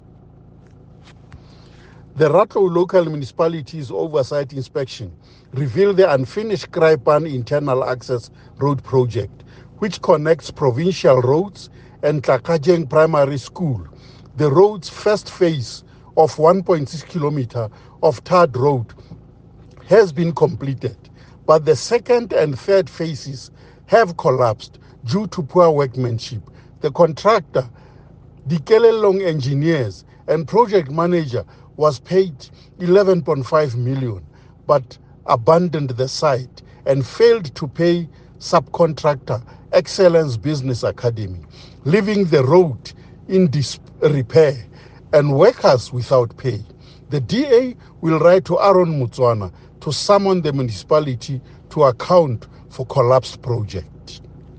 Note to Broadcasters: Please find linked soundbite in
English by Winston Rabotapi MPL.